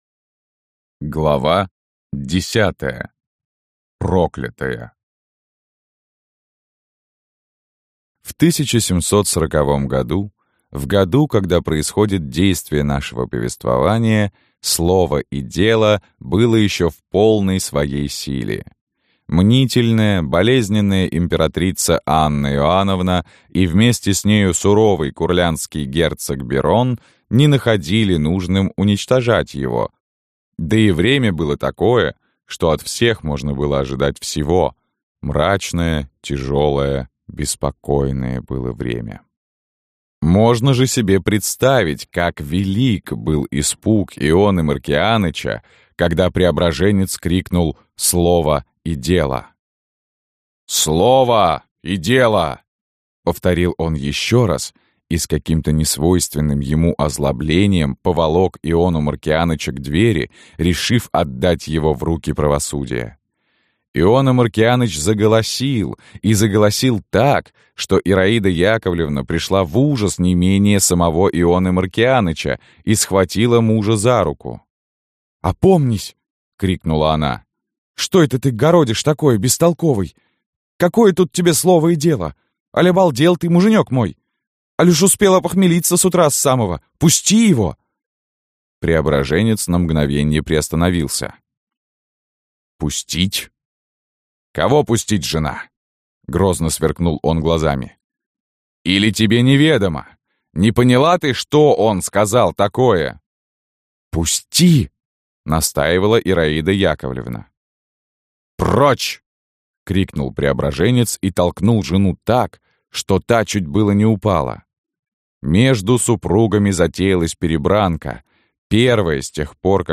Аудиокнига Салтычиха | Библиотека аудиокниг